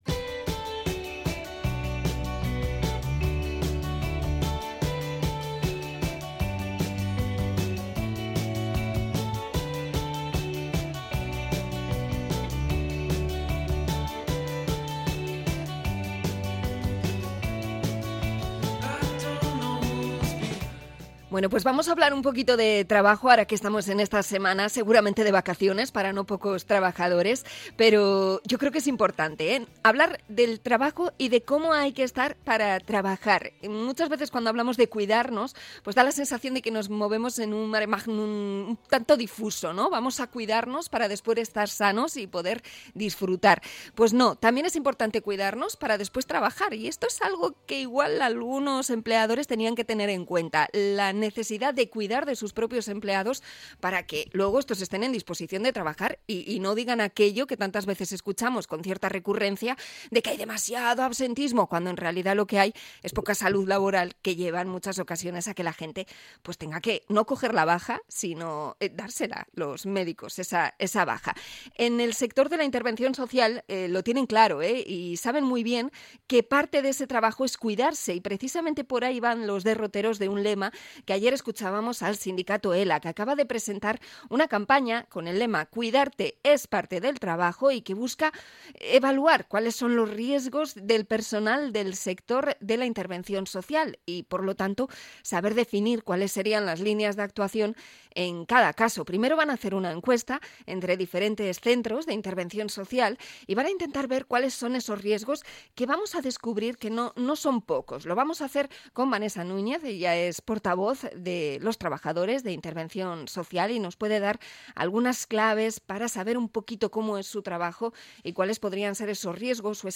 Entrevista a delegada de ELA en el sector de Intervención Social